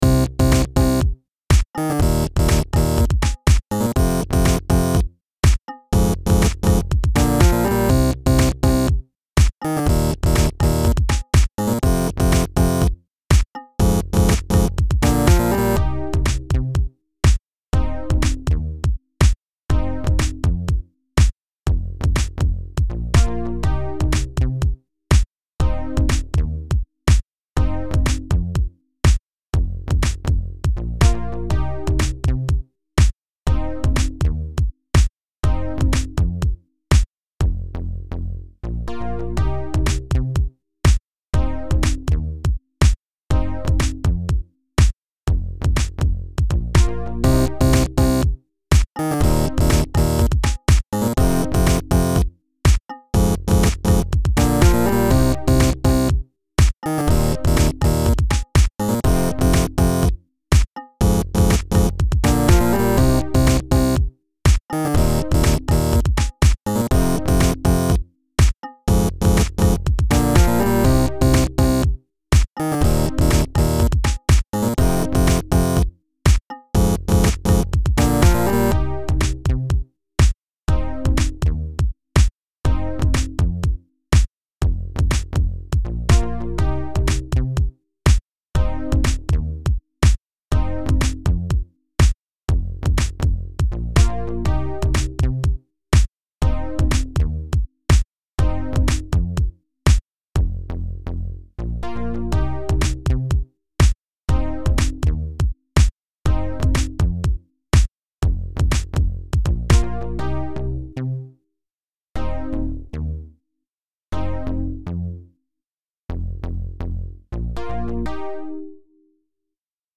The distorted synth is saved somewhere.